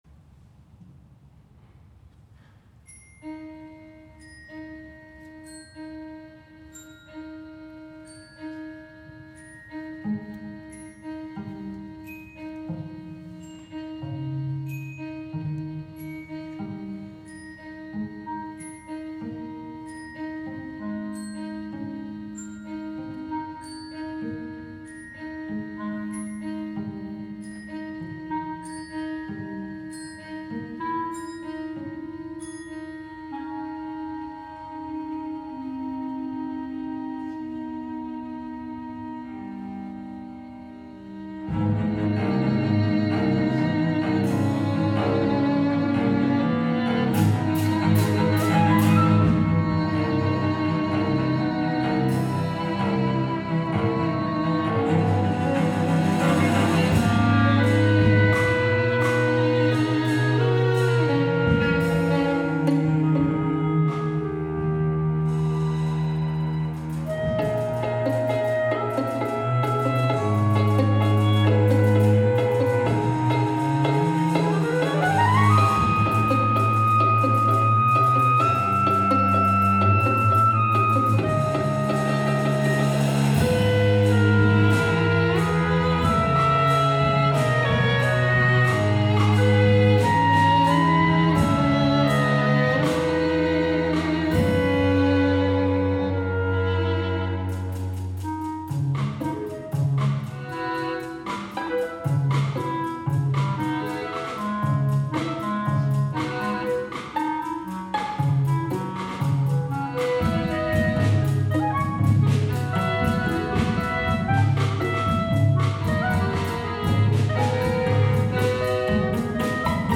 Violin
Clarinet
Cello
Drum Set and Glockenspiel